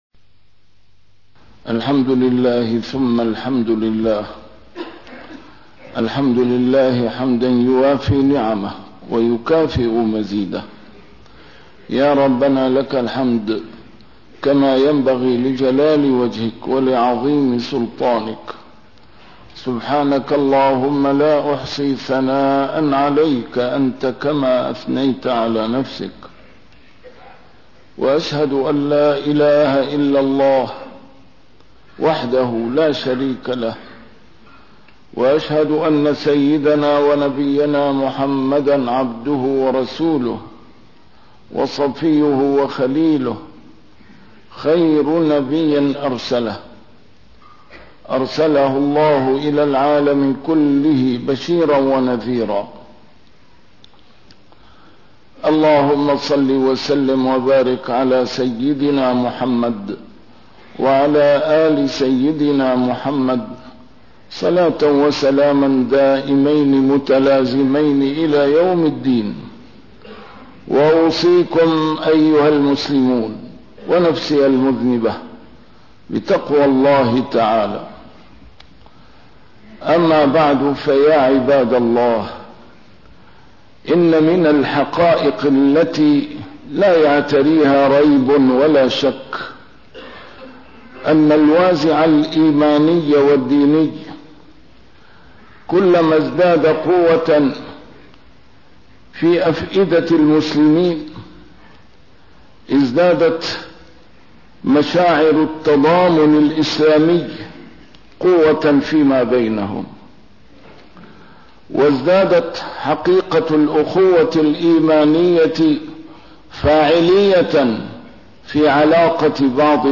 A MARTYR SCHOLAR: IMAM MUHAMMAD SAEED RAMADAN AL-BOUTI - الخطب - ضعف تضامن المسلمين نتيجةٌ لضعف الوازع الديني